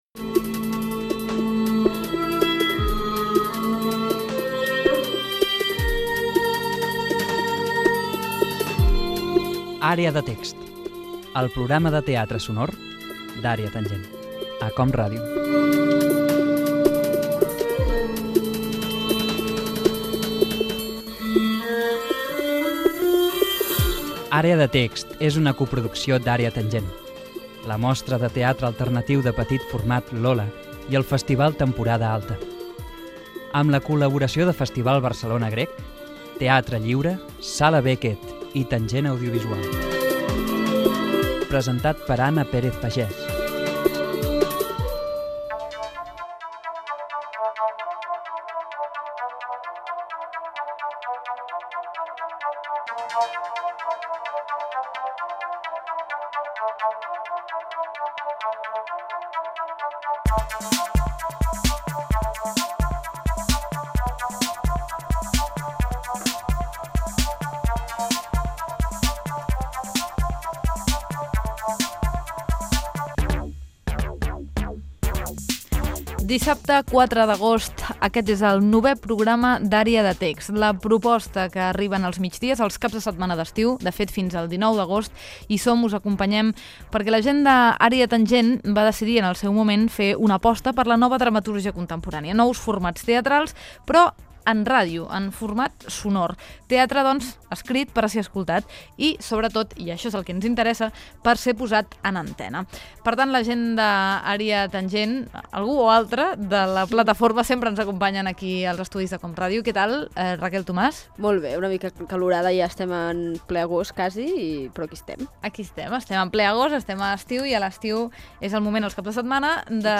Ficció
FM
Fragment extret de l'arxiu sonor de COM Ràdio